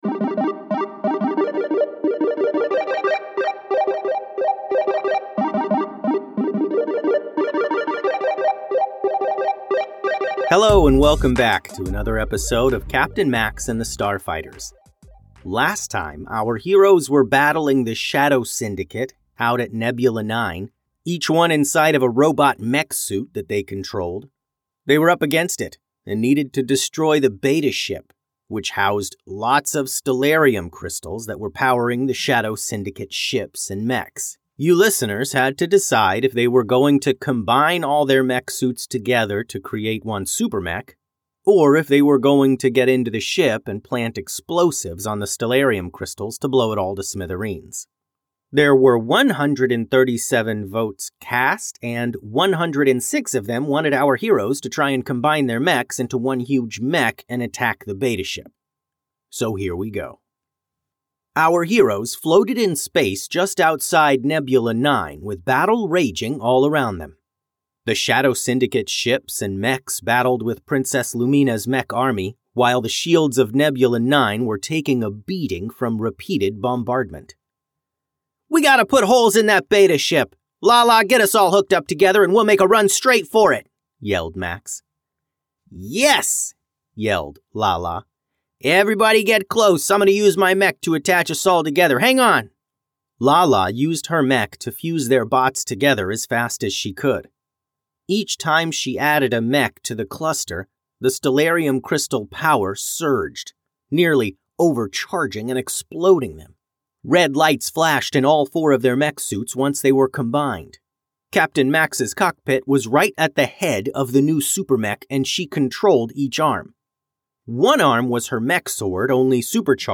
Bedtime Stories Sci-Fi
Audio Drama